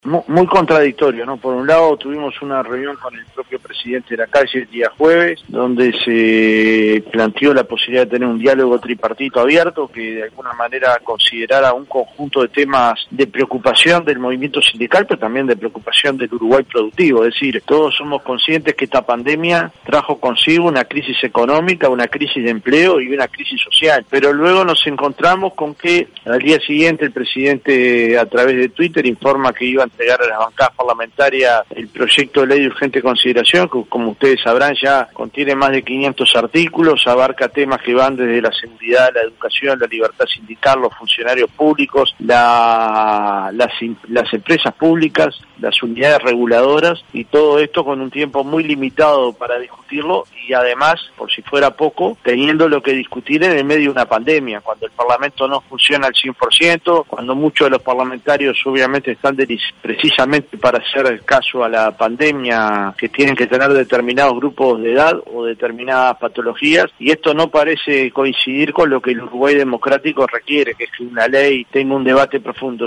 En diálogo con La Voz de Cofe en 970 Universal, enfatizó en que se requiere “un debate profundo”.